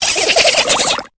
Cri de Cradopaud dans Pokémon Épée et Bouclier.